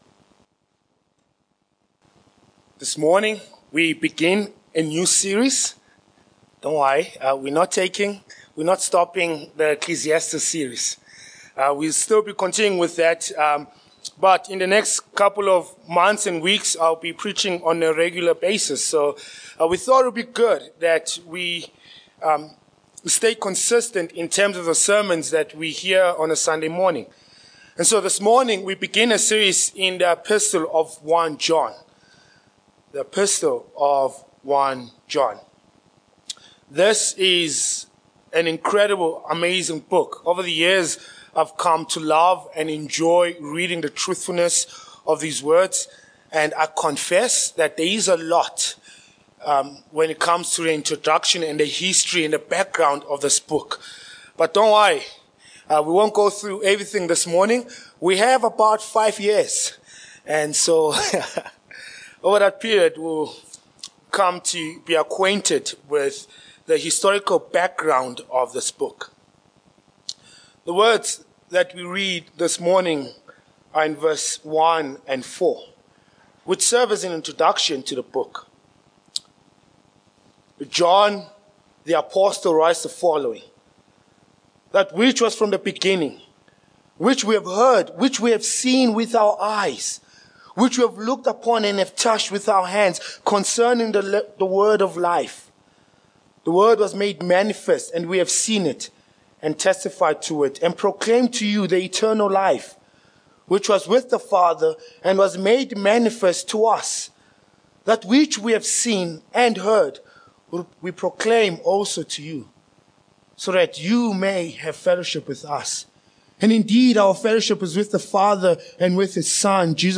1 John 1:1-4 Service Type: Morning Passage